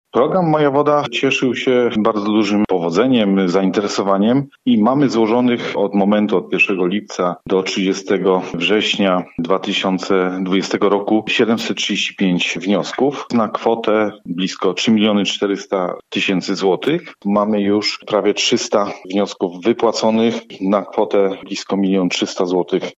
Mówi Mariusz Herbut, prezes Wojewódzkiego Funduszu Ochrony Środowiska w Zielonej Górze: